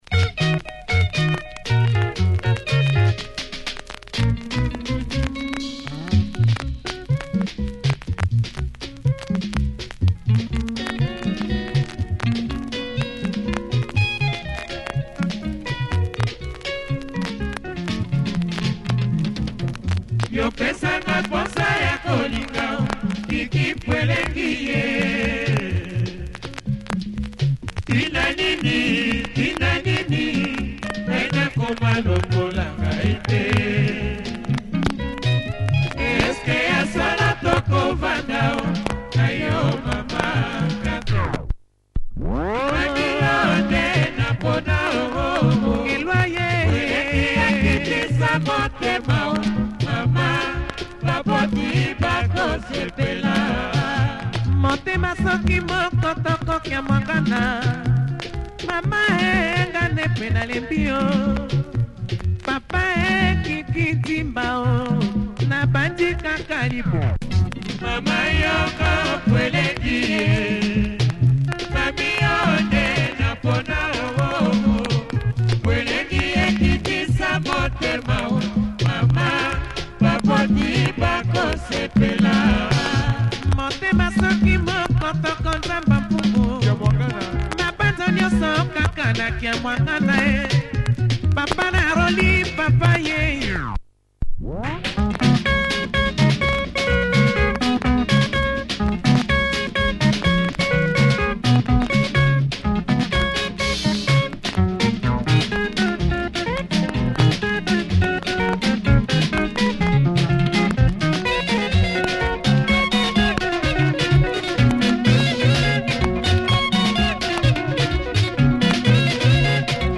Nice Lingala